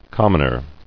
[com·mon·er]